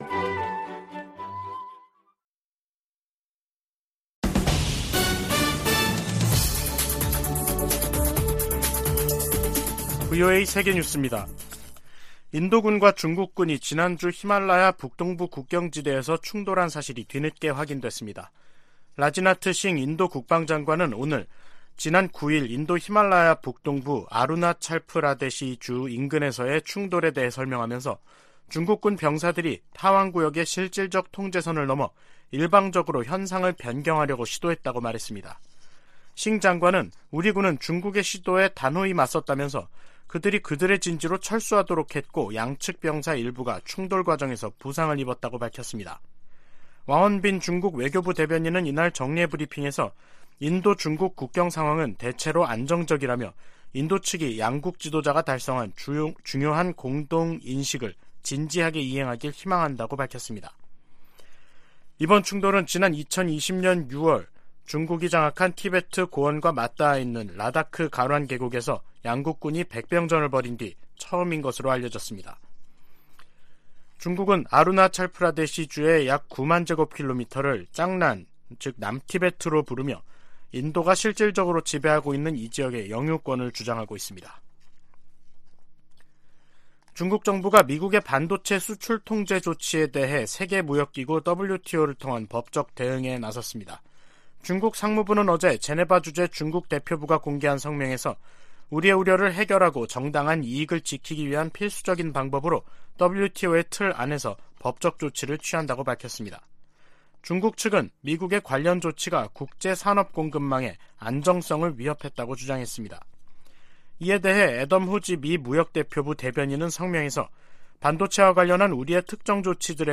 VOA 한국어 간판 뉴스 프로그램 '뉴스 투데이', 2022년 12월 13일 2부 방송입니다. 미국과 한국 외교당국 차관보들이 오늘 서울에서 만나 북한 비핵화를 위한 국제사회의 공동 대응을 거듭 강조했습니다. 유럽연합 EU가 북한 김정은 정권의 잇따른 탄도미사일 발사 등에 대응해 북한 국적자 8명과 기관 4곳을 독자 제재 명단에 추가했습니다.